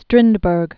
(strĭndbûrg, strĭn-), (Johan) August 1849-1912.